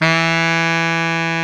Index of /90_sSampleCDs/Roland L-CDX-03 Disk 1/SAX_Sax Ensemble/SAX_Solo Sax Ens
SAX B.SAX 10.wav